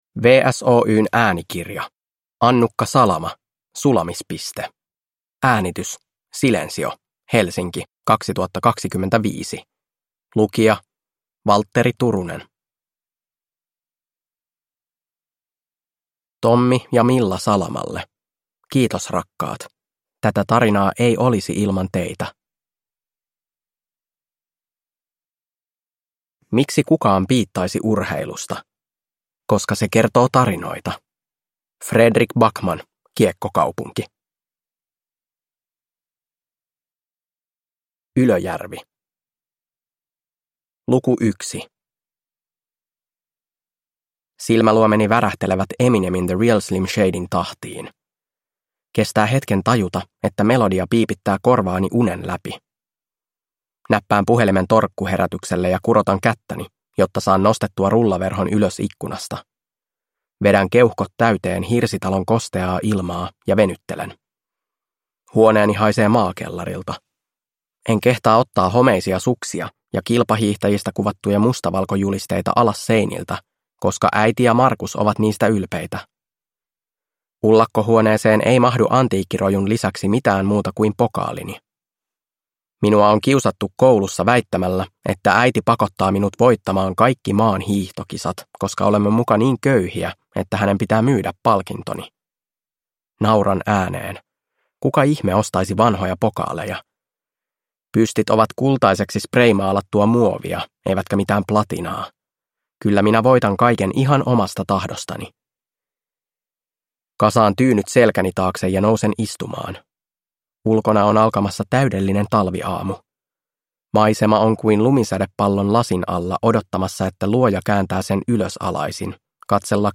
Sulamispiste – Ljudbok